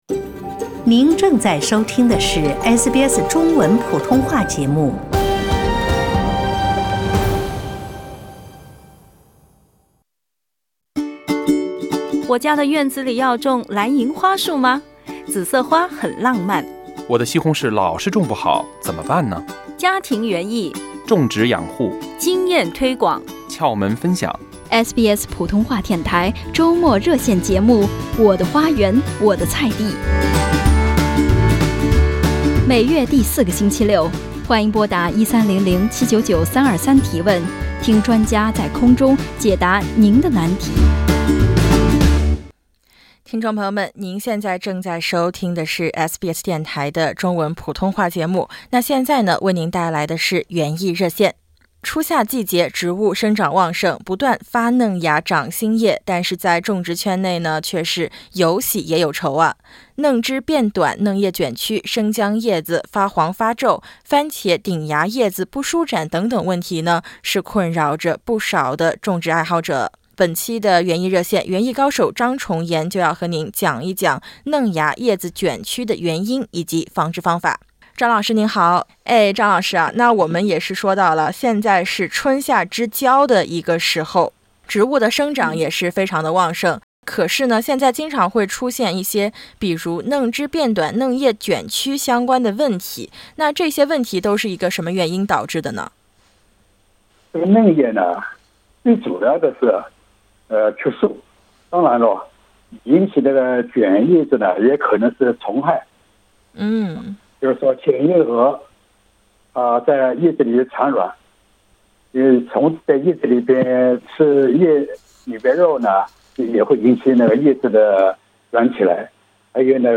欢迎您点击封面音频，收听完整采访。